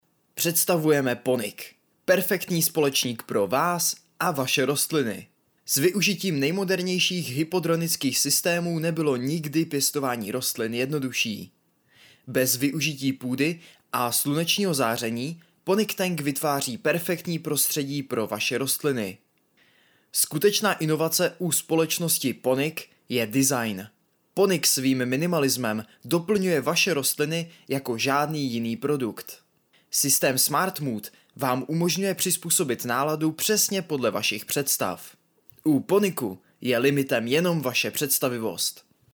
Hlas pro Vaše video! (voiceover)
K dispozici mám profesionální mikrofon a zvuk jsem schopen sám upravit a poté odeslat v nejvyšší možné kvalitě.
ukázka_reklama_Ponic.mp3